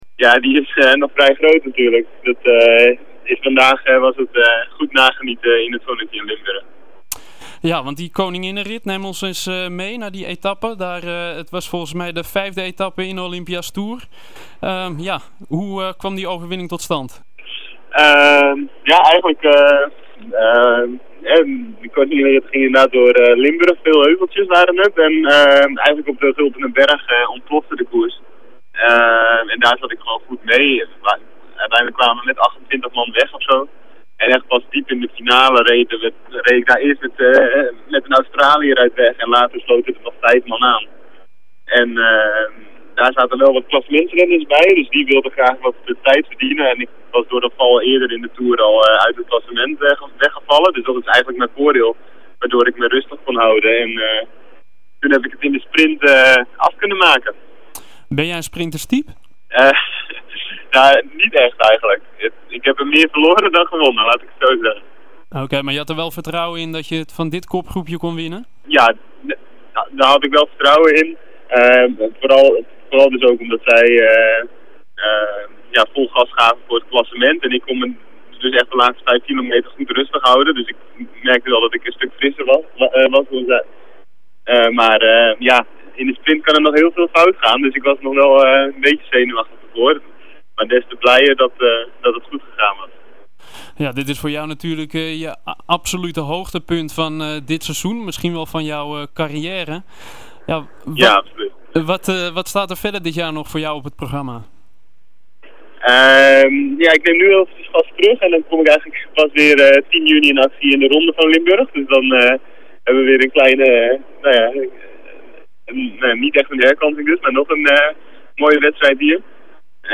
Amsterdam FM vroeg hem  dit weekend hoe groot de vreugde nog was.